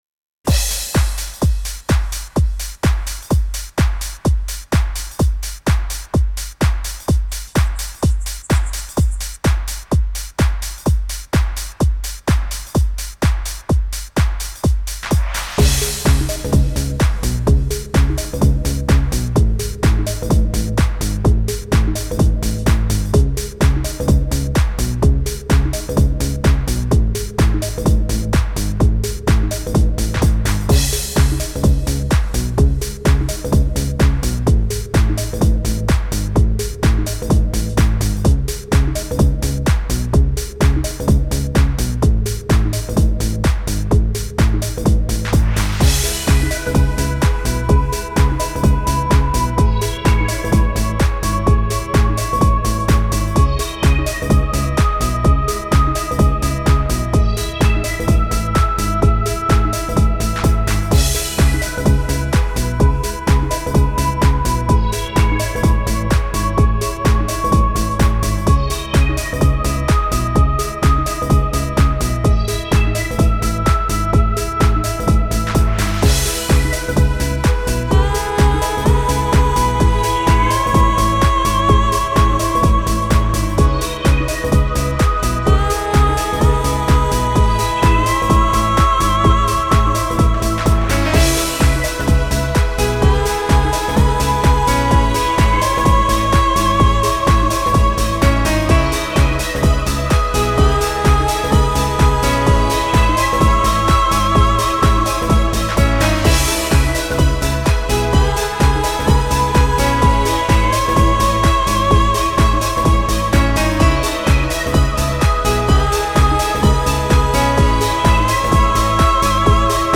Dream house Дрим хаус Dream Trance